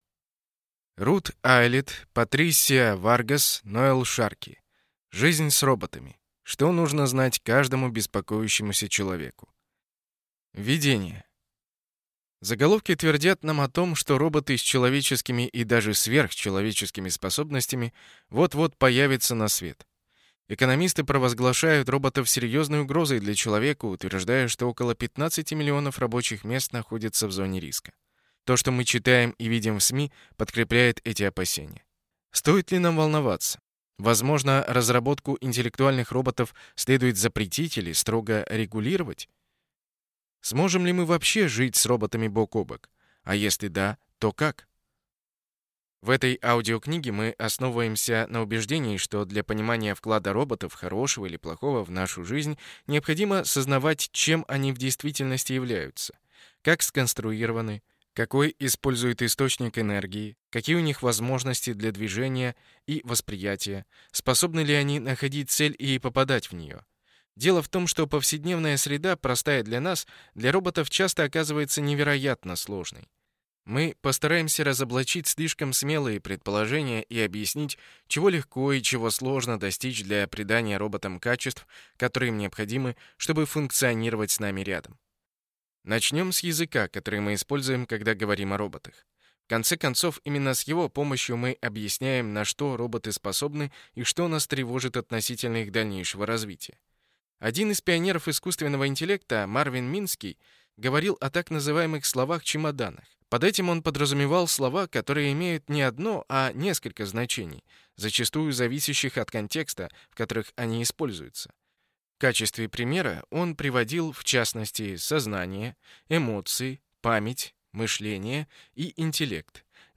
Аудиокнига Жизнь с роботами. Что нужно знать каждому беспокоящемуся человеку | Библиотека аудиокниг